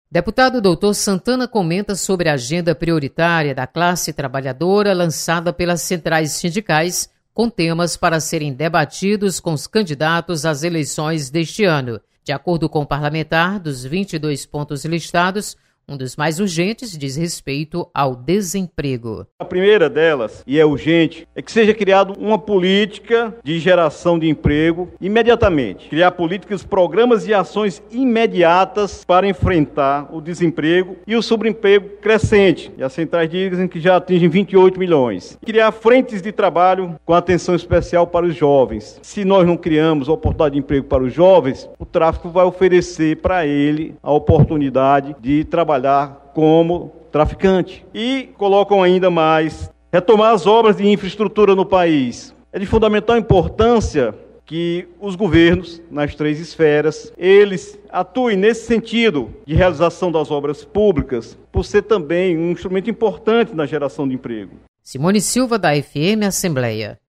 Deputada Doutor Santana defende candidaturas ligadas à classe trabalhadora. Repórter